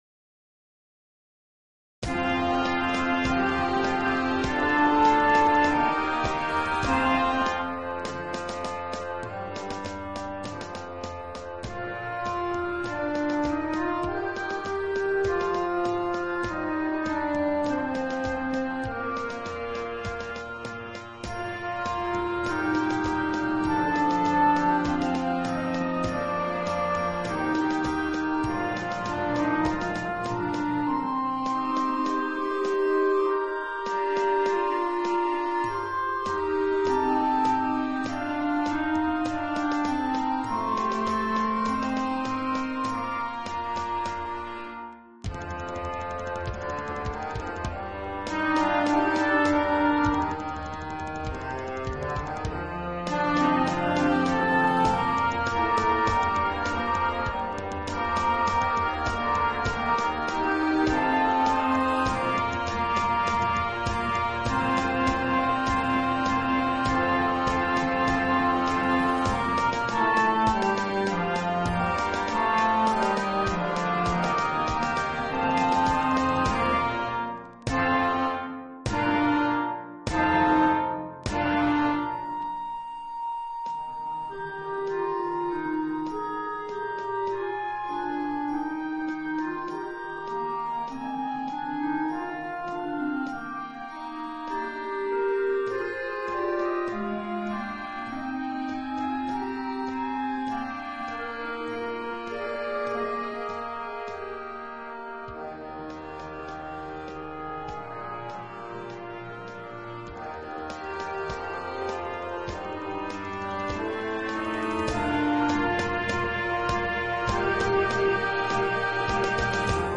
Concert Band Grade 1